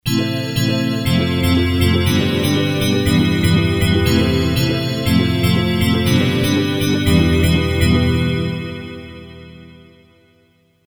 Double set of cards for both synthesizer Roland JD-800 or module JD-990.